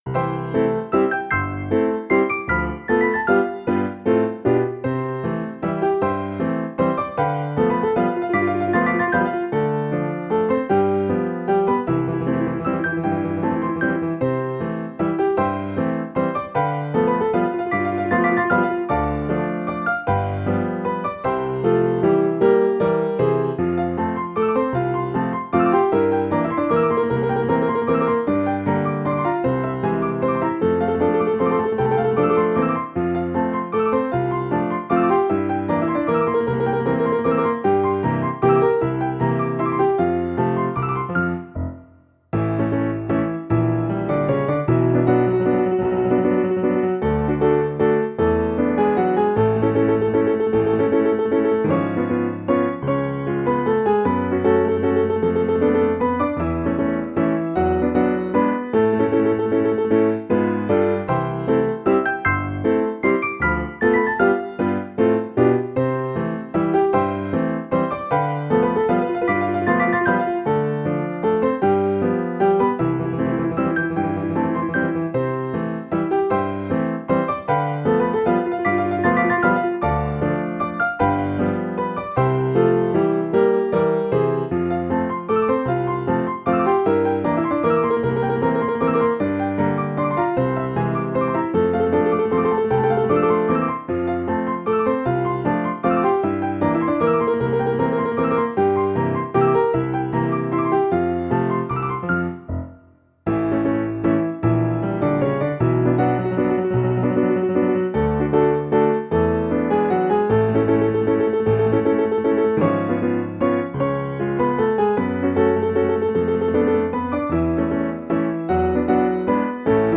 Vals. Rollo de pianola. La Garriga, Barcelona, hacia 1930.